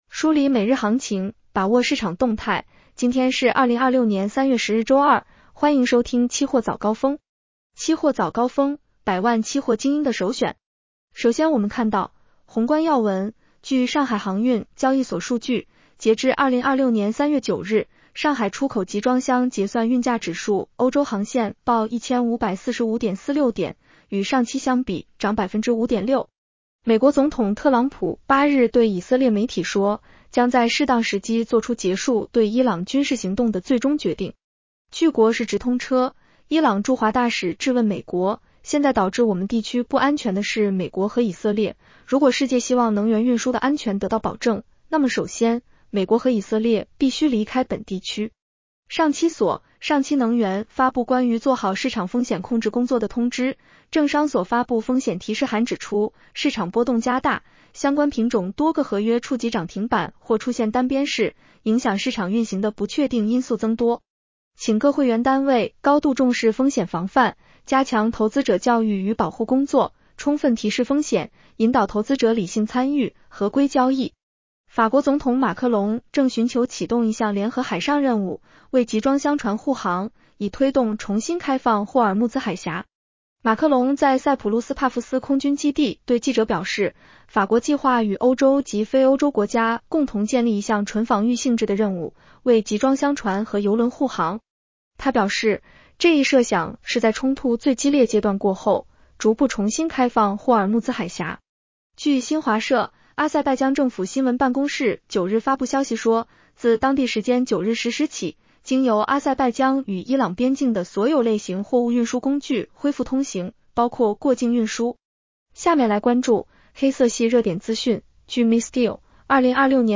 期货早高峰-音频版 女声普通话版 下载mp3 热点导读 1.郑商所调整部分期货合约交易指令每次最小开仓下单量。